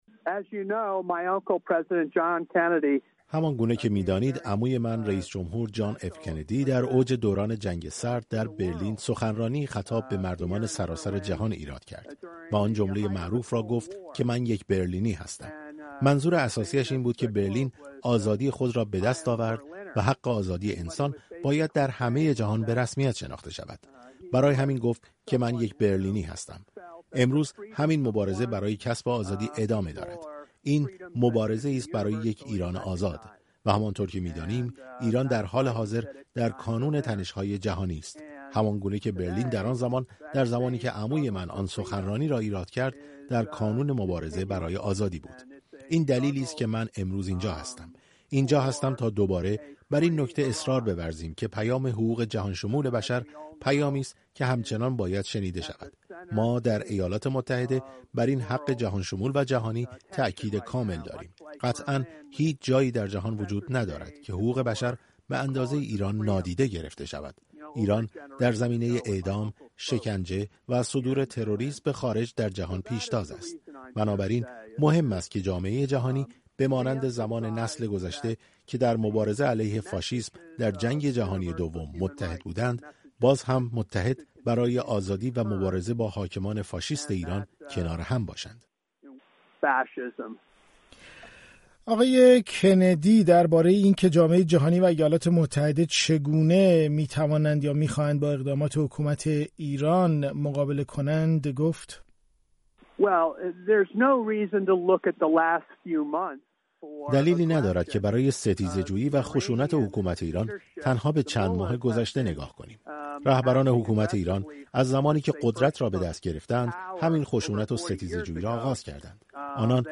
گفت‌وگوی رادیو فردا با پاتریک کندی، نماینده پیشین کنگره آمریکا